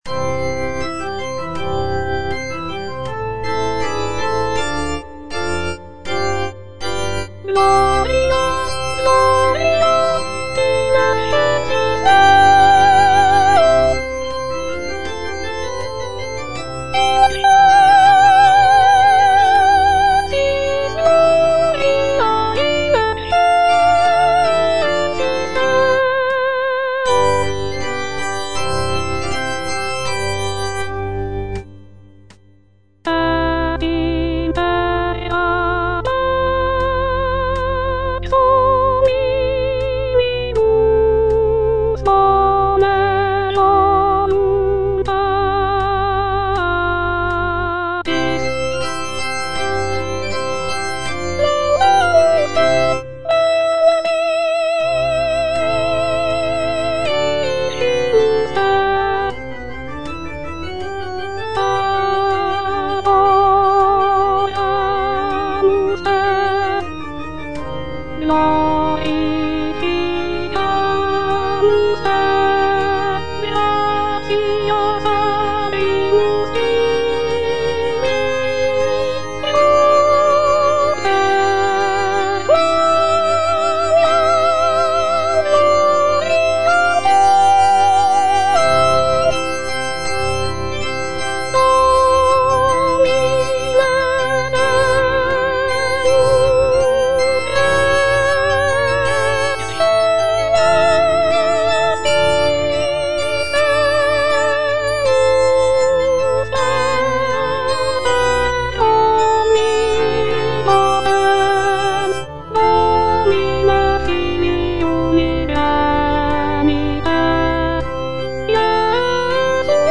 C.M. VON WEBER - MISSA SANCTA NO.1 Gloria - Soprano (Voice with metronome) Ads stop: auto-stop
The work features a grand and powerful sound, with rich harmonies and expressive melodies.